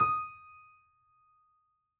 piano_075.wav